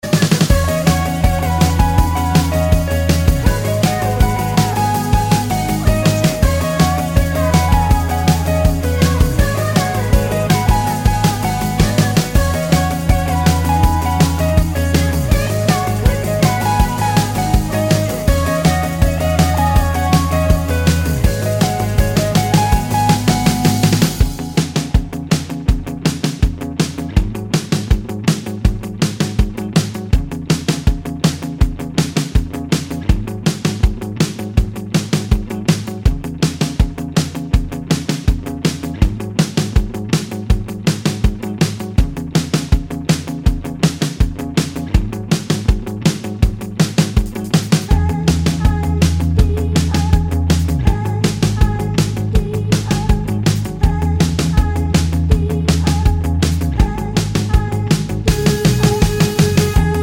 With Explicit Backing Vocals Pop (2010s) 3:42 Buy £1.50